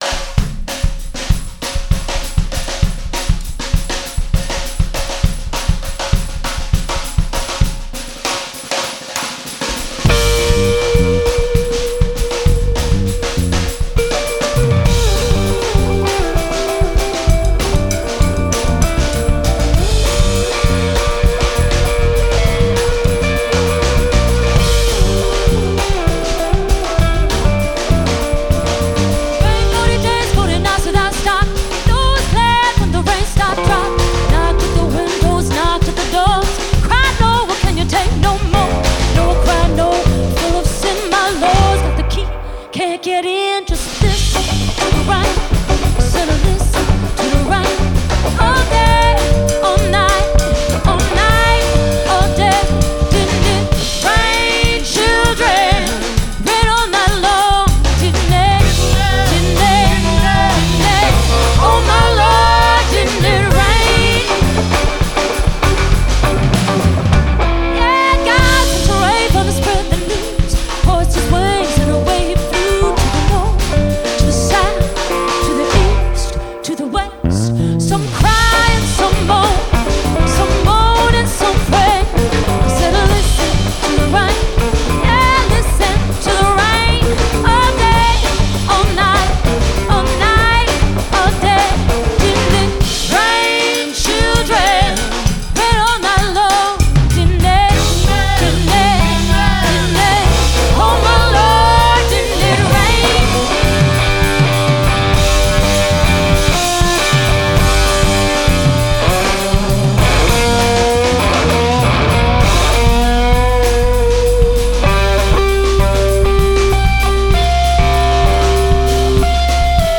We believe in presenting artists exactly as they perform.